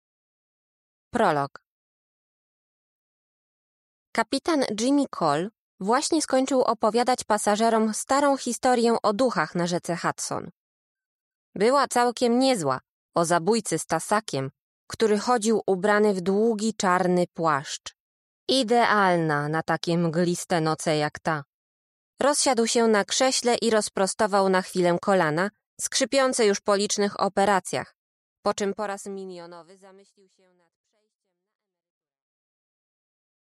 Аудиокнига Porwana | Библиотека аудиокниг